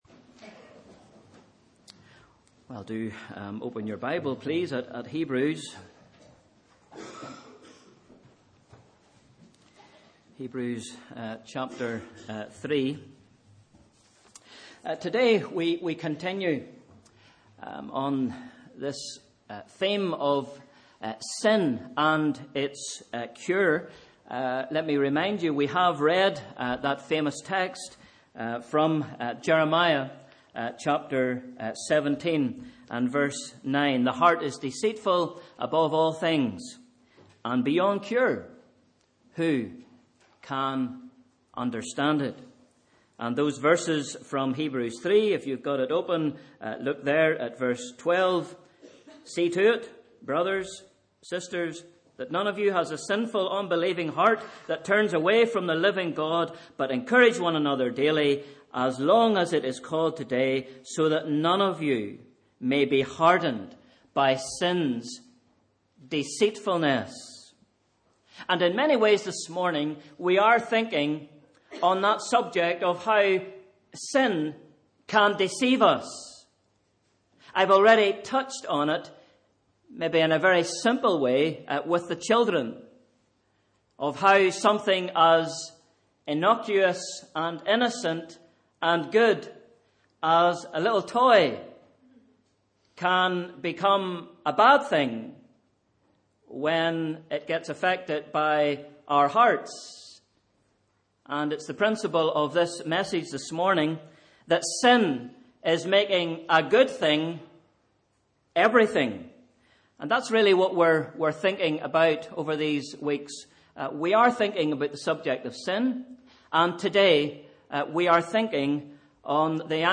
Sunday 7th February 2016 – Morning Service